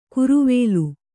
♪ kuruvēlu